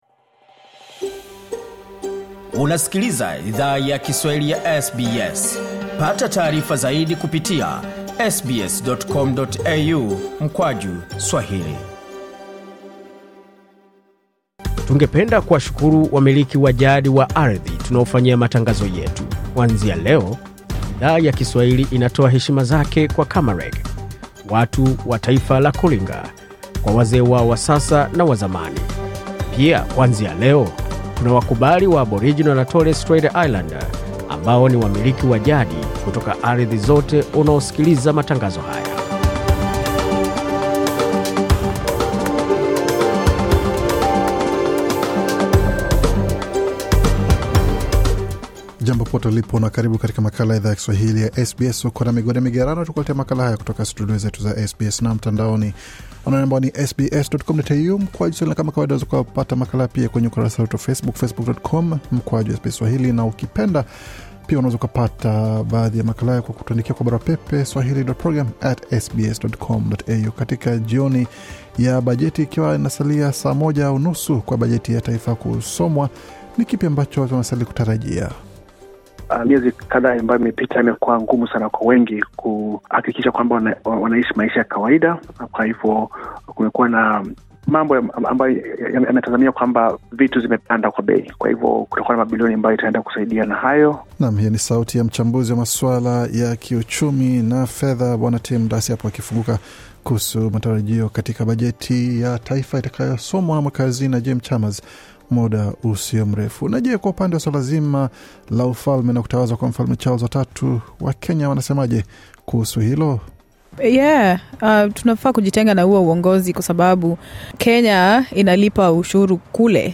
Taarifa ya Habari 9 Mei 2023